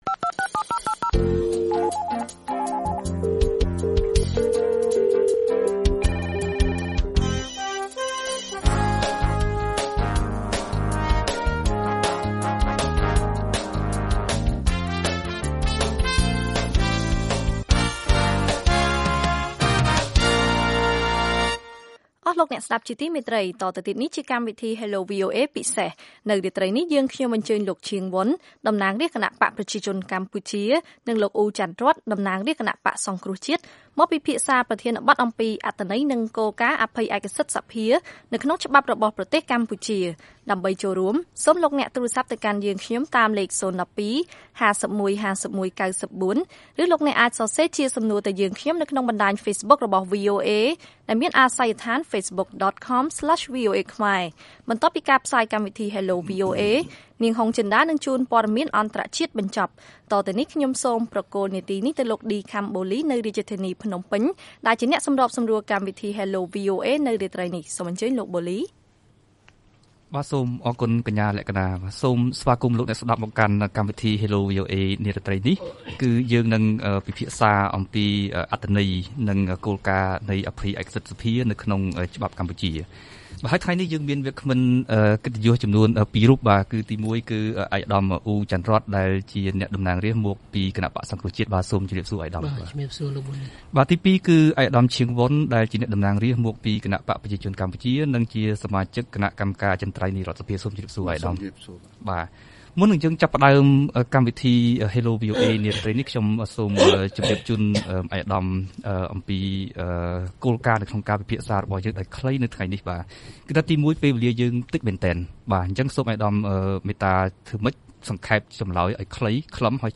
លោក អ៊ូ ច័ន្ទរ័ត្ន តំណាងរាស្ត្រមកពីគណបក្សសង្គ្រោះជាតិ និងលោក ឈាង វុន តំណាងរាស្ត្រមកពីគណបក្សប្រជាជនកម្ពុជា បានពិភាក្សាអំពីអត្ថន័យ និងគោលការណ៍នៃអភ័យឯកសិទ្ធិសភានេះ នៅក្នុងកម្មវិធី Hello VOA ពិសេស កាលពីថ្ងៃអង្គារ ទី៣១ ខែឧសភា ឆ្នាំ២០១៦។